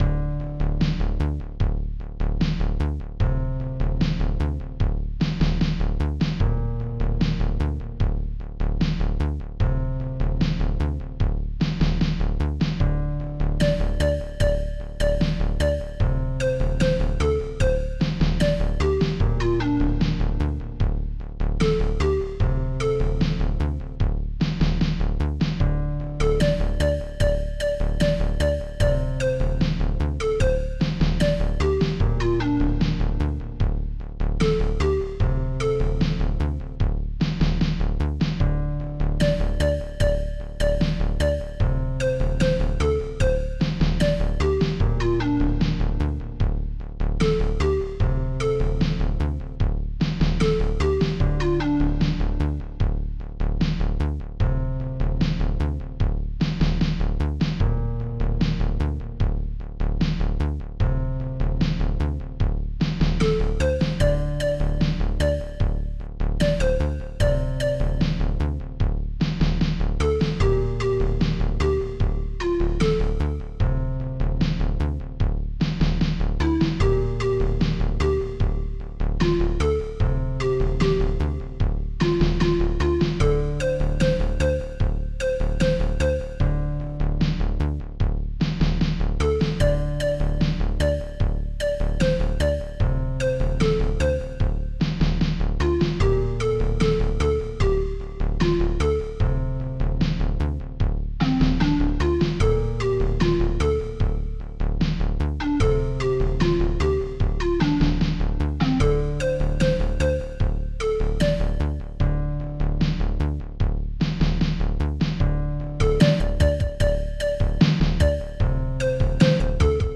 Protracker Module
2 channels
Instruments ST-43:bass ST-43:BreakBdrum ST-43:Breaksnare ST-48:PANMALLET1 ST-01:Clap ST-03:M1-GUITARlow2